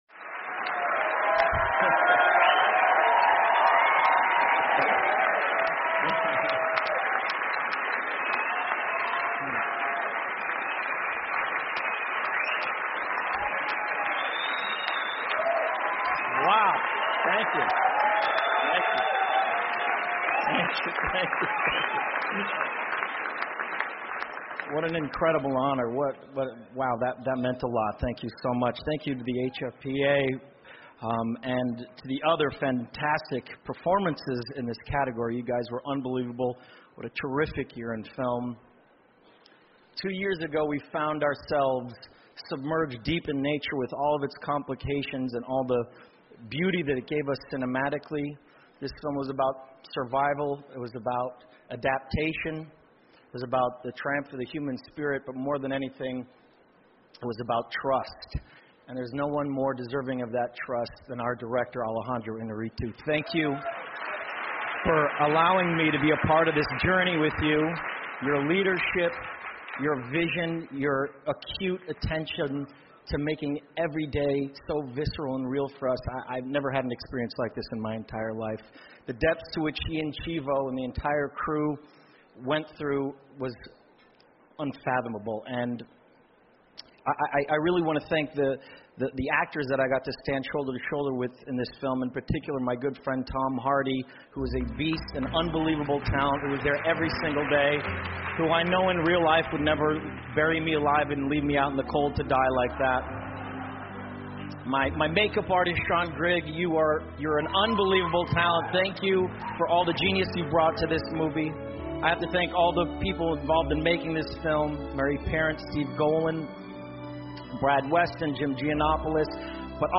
欧美人文风情第201篇:莱昂纳多2016金球奖得奖感言 听力文件下载—在线英语听力室